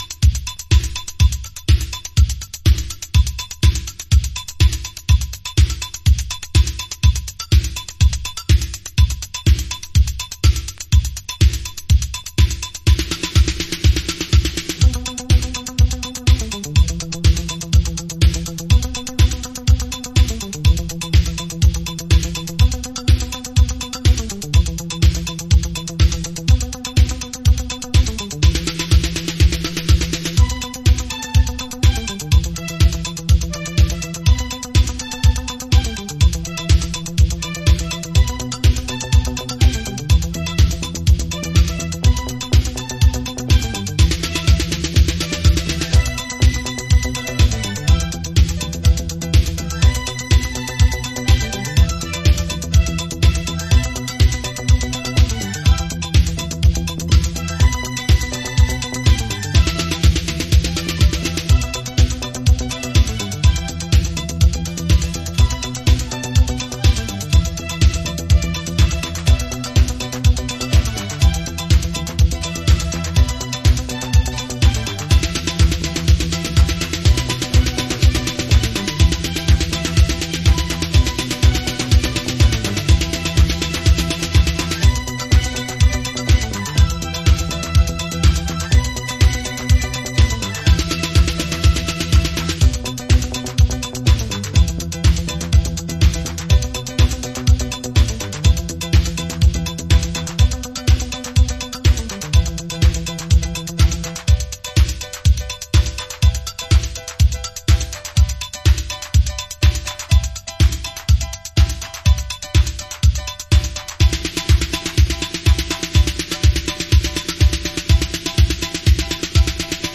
Chicago Oldschool / CDH
まだまだ、イタロや80'sディスコの影響が垣間見られるトラックス。